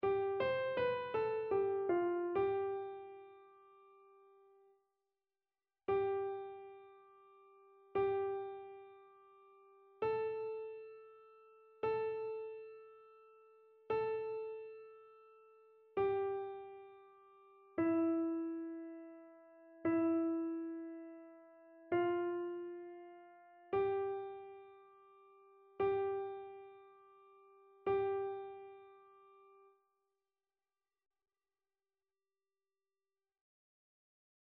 Chœur
annee-a-temps-ordinaire-33e-dimanche-psaume-127-soprano.mp3